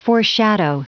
Prononciation du mot foreshadow en anglais (fichier audio)
Prononciation du mot : foreshadow